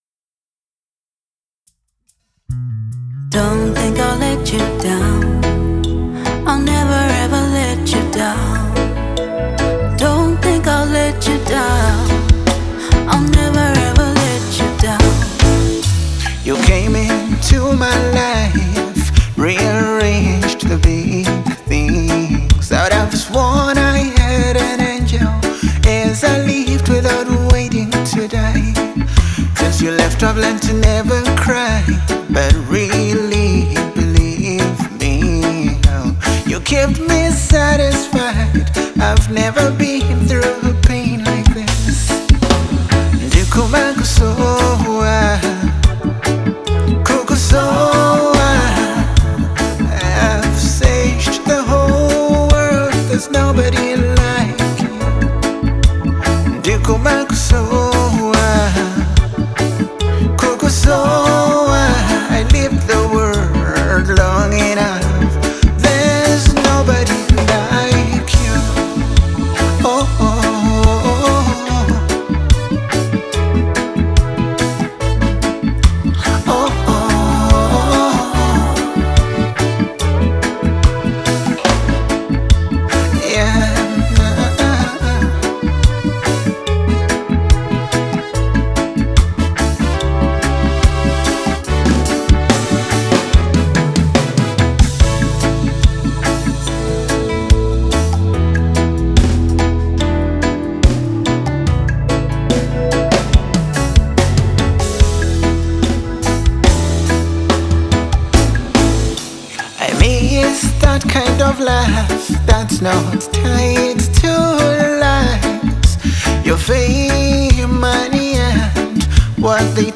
Genre: Reggae
Its tone is restrained, honest, and intentionally human.